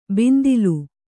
♪ bindilu